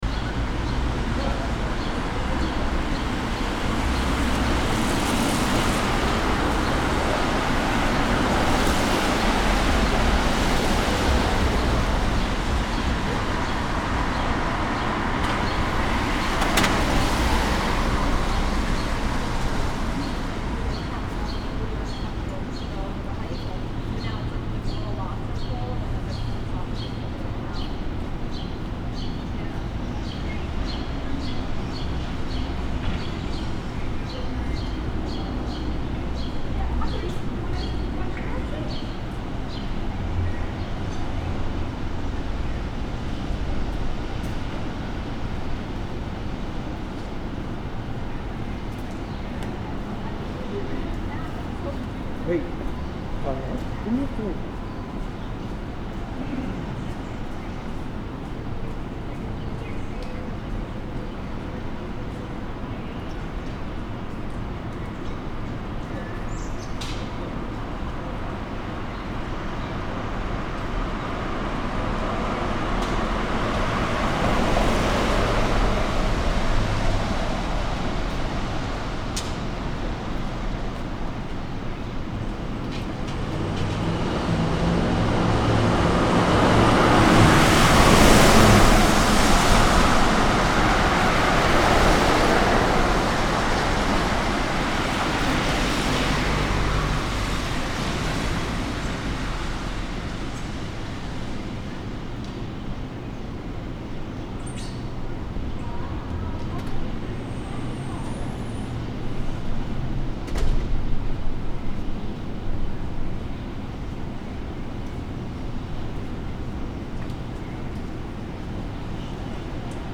From a fire escape in New York
A sound from New York's lockdown recorded from an apartment fire escape on April 23 2020 in the Lower East Side.